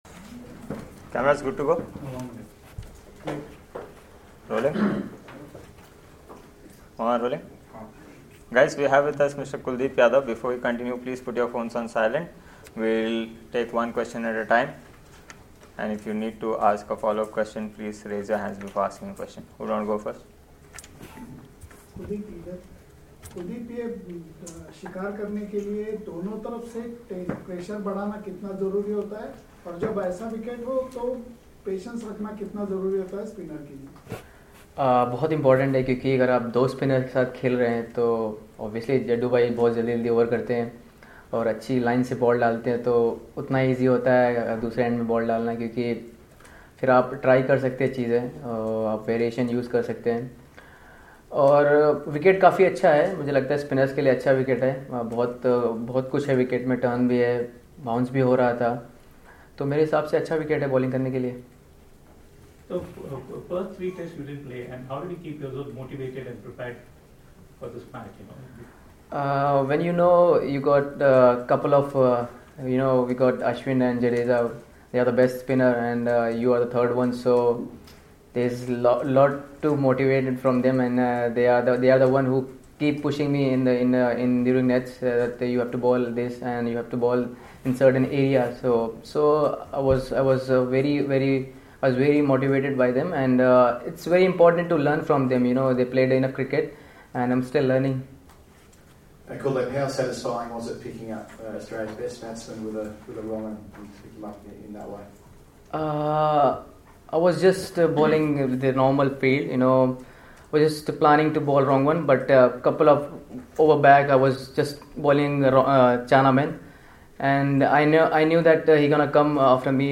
Kuldeep Yadav, Member, Indian Cricket Team, speaks with the media in Sydney on Saturday, January 5 after Day 3 of the 4th Test against Australia.